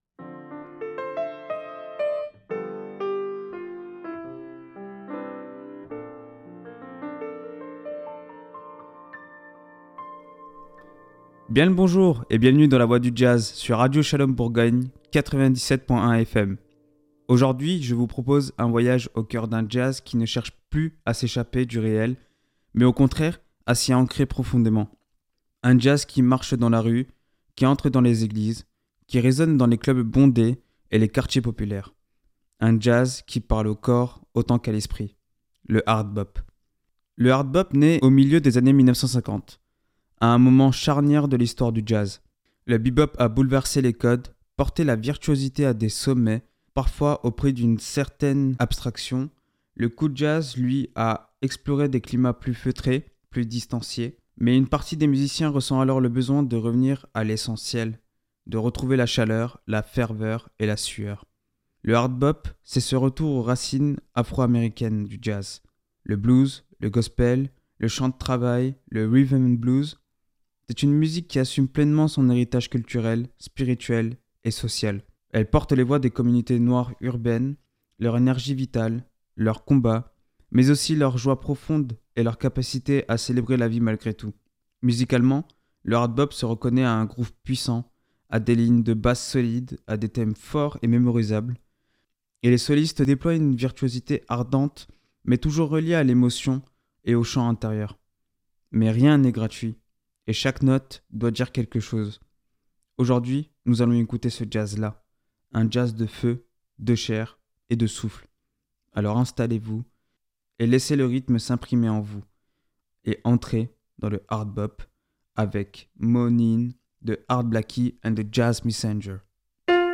Nourrie de blues, de gospel, de rhythm and blues et de traditions afro-américaines, cette musique puissante et fervente fait entendre la voix des quartiers populaires, la ferveur spirituelle, la sueur des clubs et l’énergie collective. Un jazz qui groove, qui rassemble, et qui parle autant au cœur qu’à l’esprit.